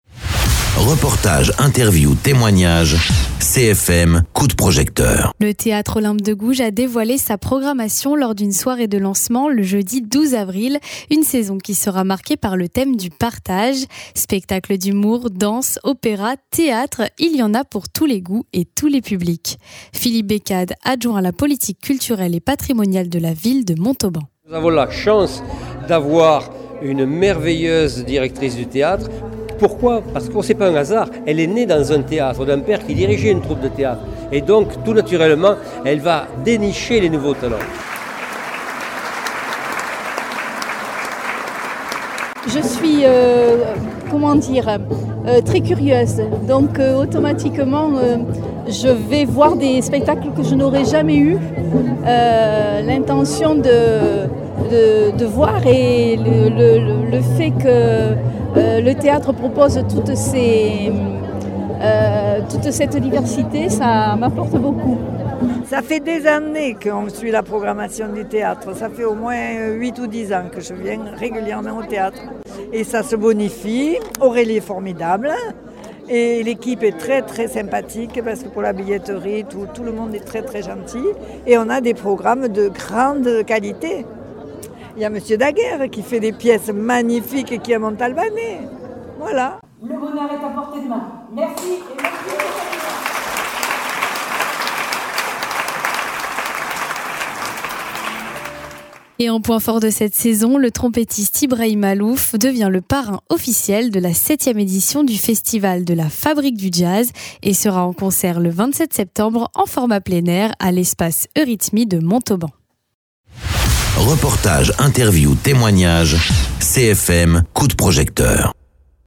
Interviews
Invité(s) : Philippe Bécade, adjoint à la politique culturelle et patrimoniale de la ville de Montauban